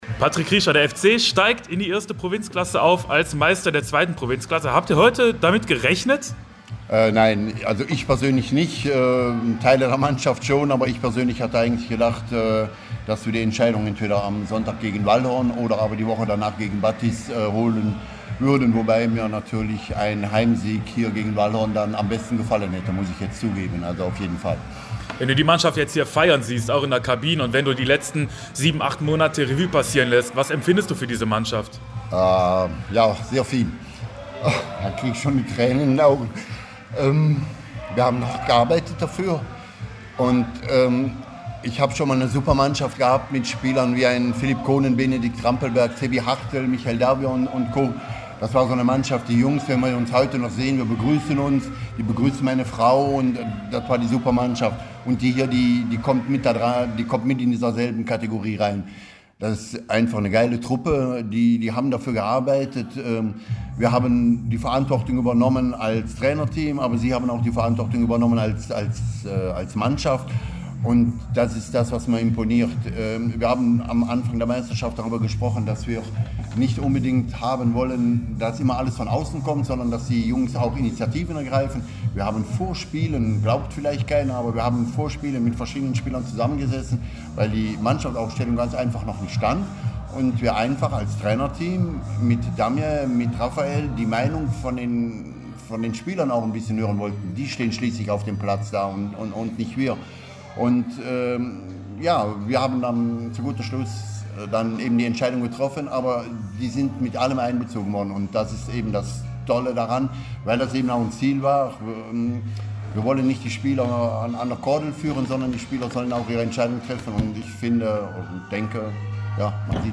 Hier gibt es das komplette Intreview: